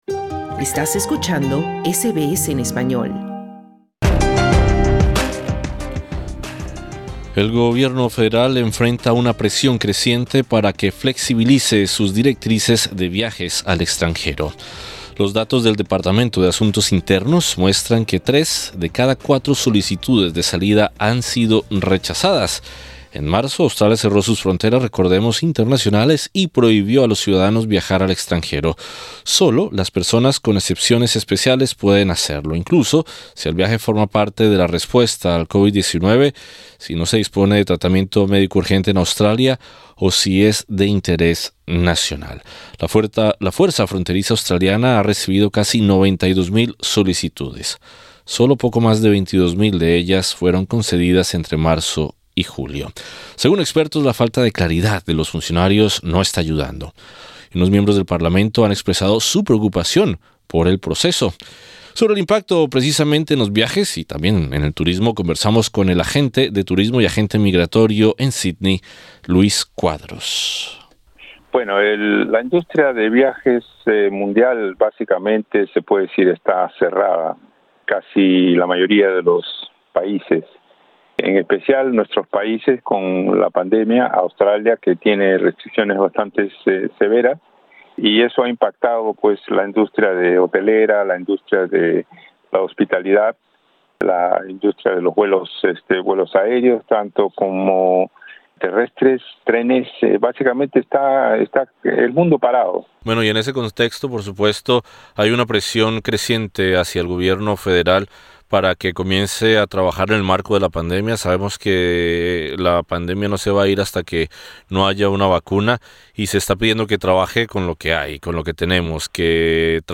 Sobre el impacto en los viajes y el turismo, conversamos con el agente de turismo y agente migratorio